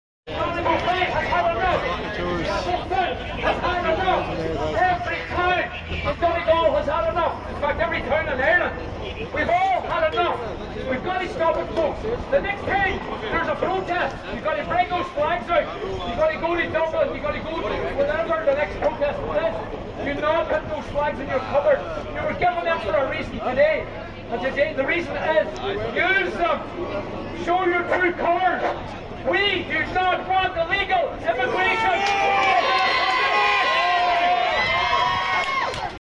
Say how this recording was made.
who can be heard at the protest